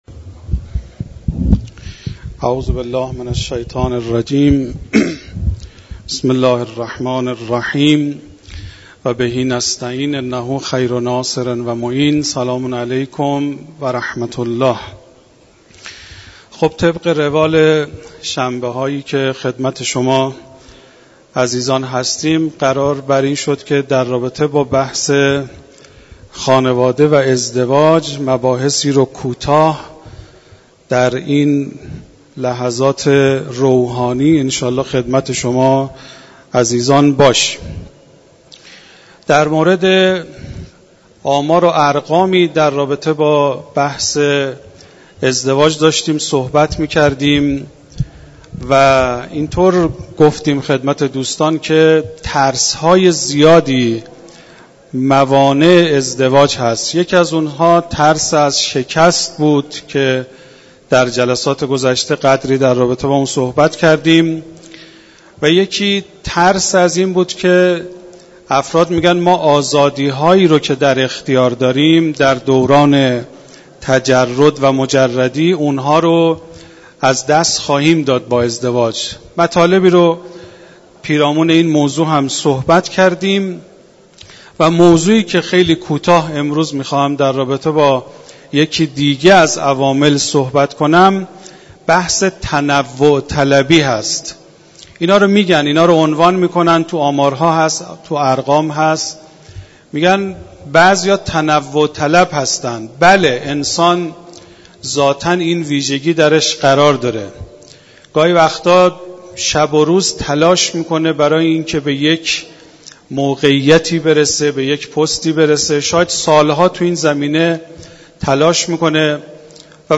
بیان مباحث مربوط به خانواده و ازدواج در کلام مدرس حوزه و دانشگاه در مسجد دانشگاه کاشان